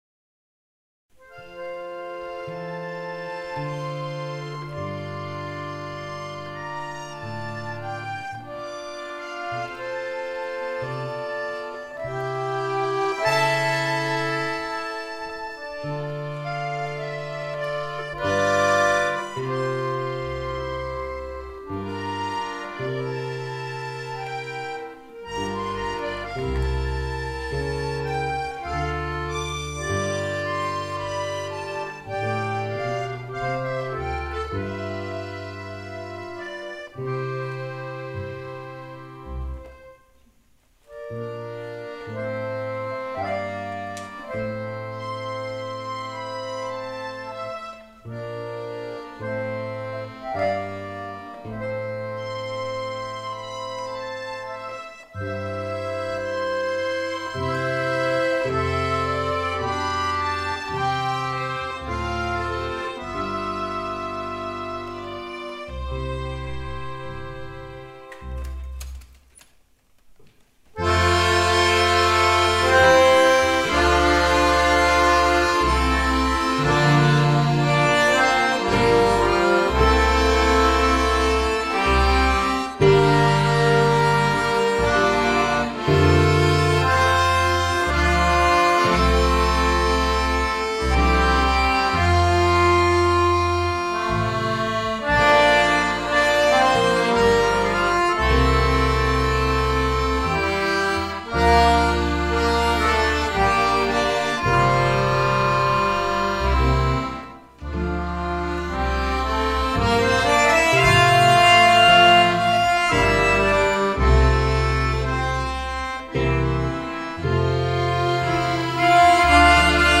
2010 – Akkordeonorchester Neustadt bei Coburg e. V.